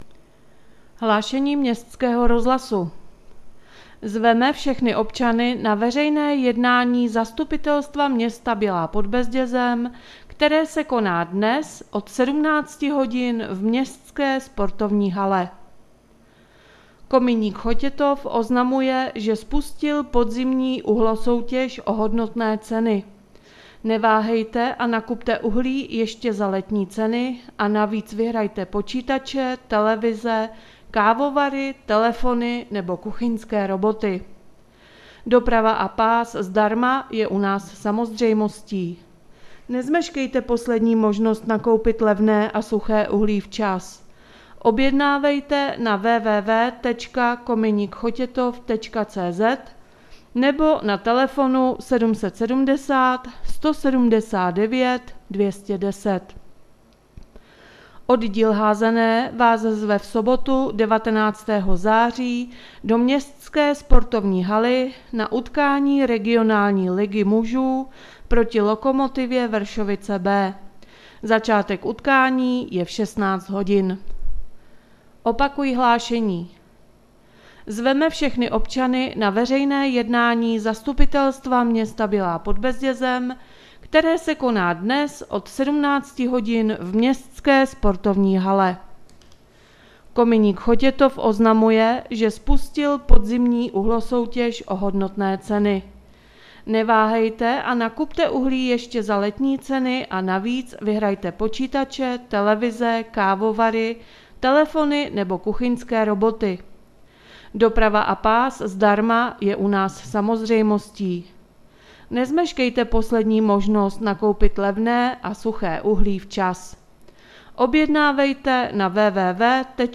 Hlůášení městského rozhlasu 16.9.2020